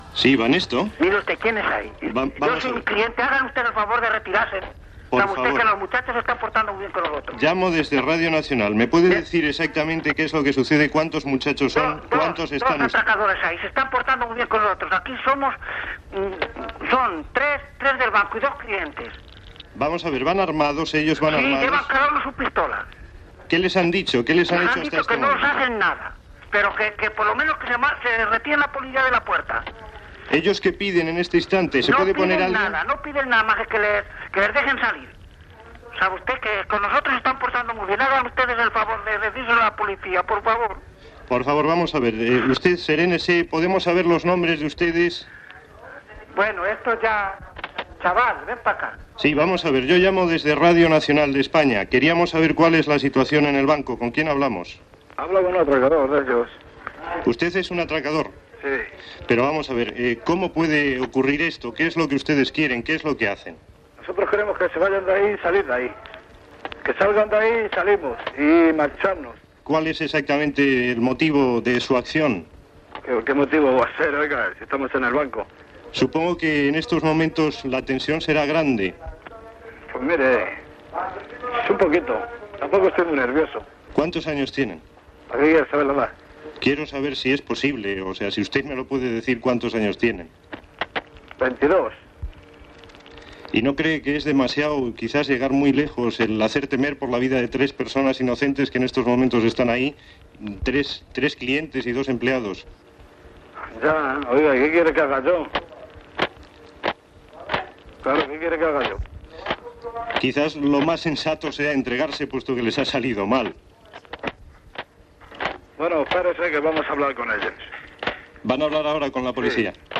Trucada telefònica per informar en directe de l'atracament a una sucursal del Banesto de Madrid amb hostatges
Informatiu